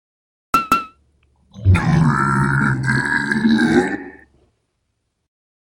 fire-3.ogg.mp3